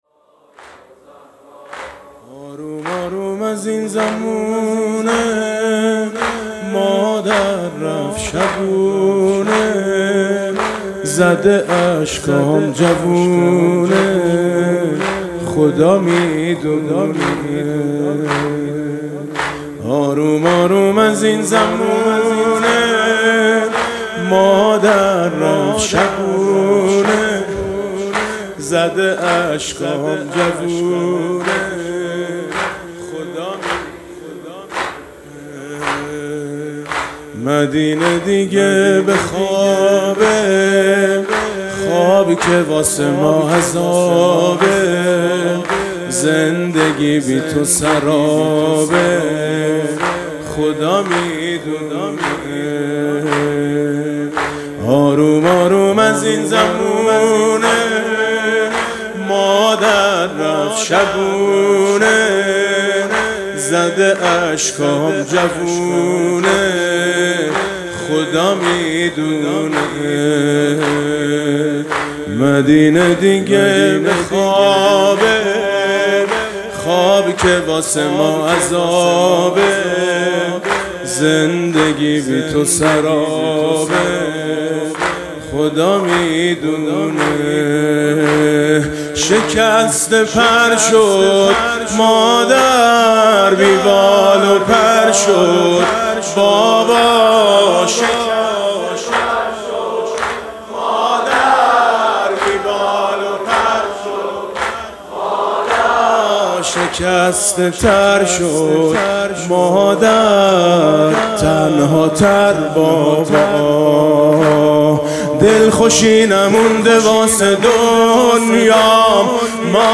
نوحه آروم آروم از این زمونه مادر رفت شبونه با صدای میثم مطیعی به مناسبت ایام فاطمیه بشنوید.
برچسب ها: میثم مطیعی فاطمیه نوحه